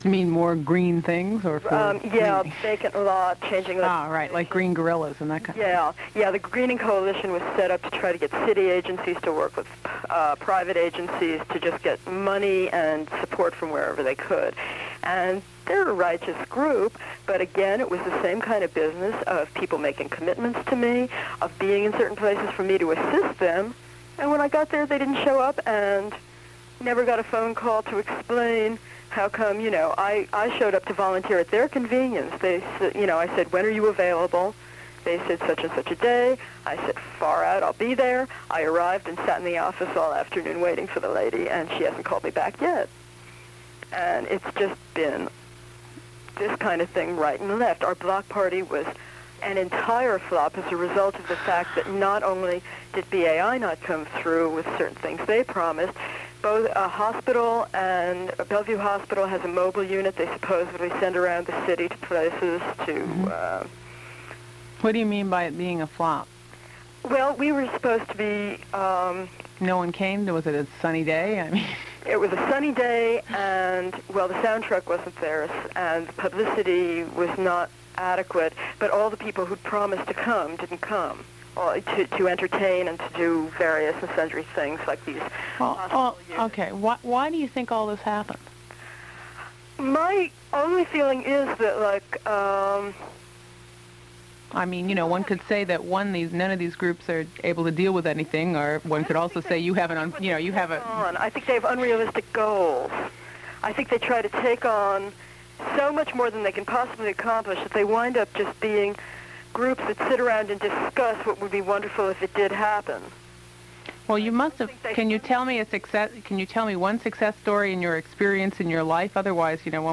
WBAI Radio Programs